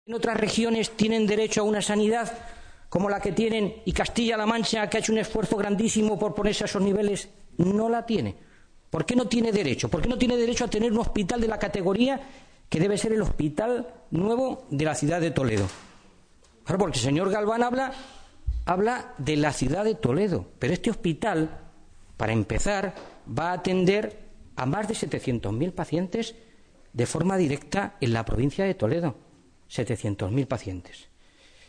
El diputado regional y portavoz socialista de la Comisión de Sanidad de las Cortes regionales, Fernando Mora, exigía hoy al Gobierno de Castilla-La Mancha que mantengan las obras del hospital de Toledo en los plazos y términos previstos.
Cortes de audio de la rueda de prensa